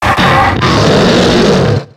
Cri de Trioxhydre dans Pokémon X et Y.